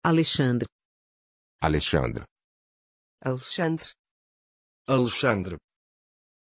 Aussprache von Alexandr
pronunciation-alexandr-pt.mp3